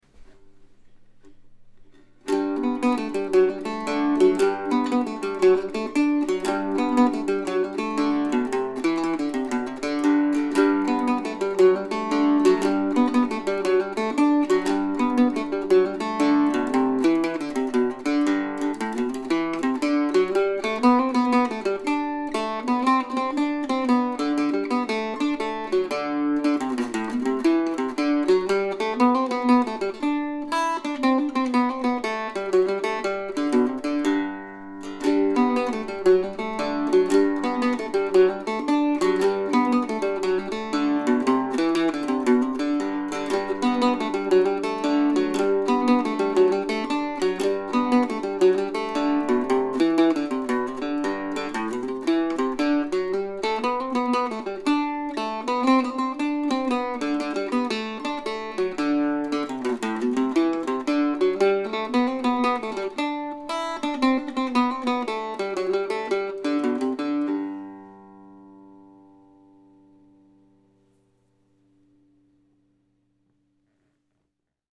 Summer Suite, August, 2020 (for Octave Mandolin or Mandocello)
I added short introductions to a couple of the pieces, I changed keys a couple of times and I added drones here and there.